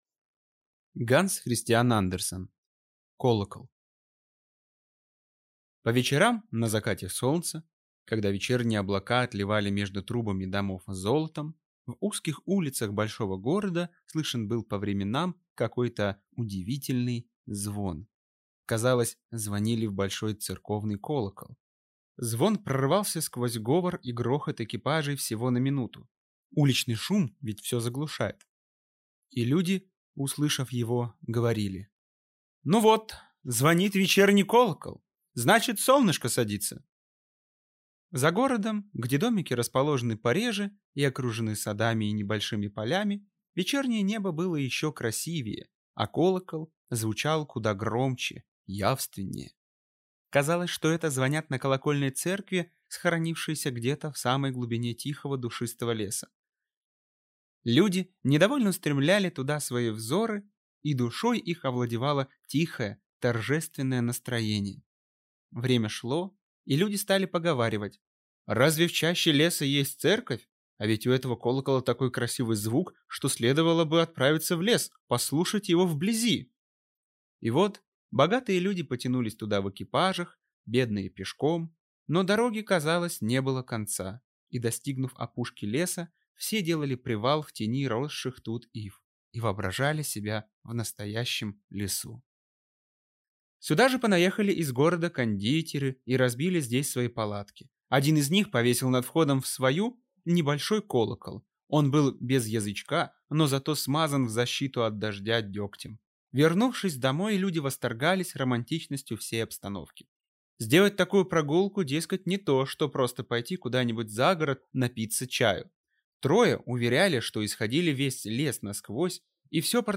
Аудиокнига Колокол | Библиотека аудиокниг